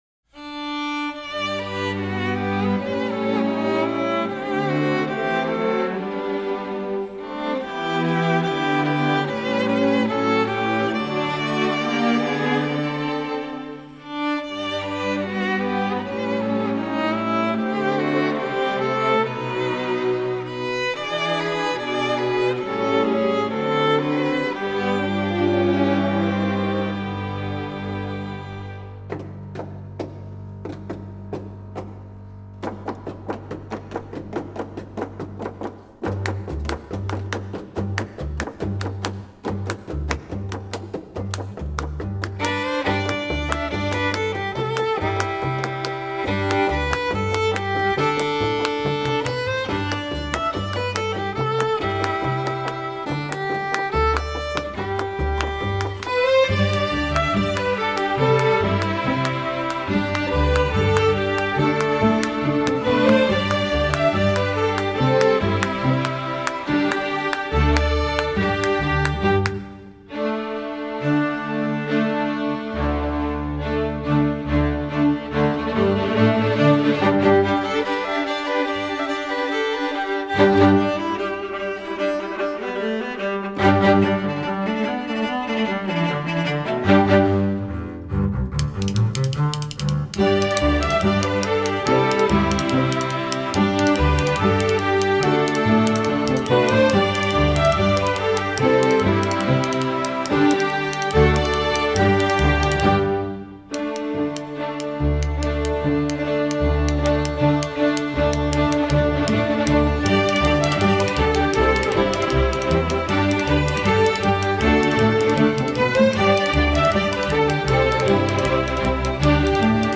instructional
Piano accompaniment part:
1st percussion part: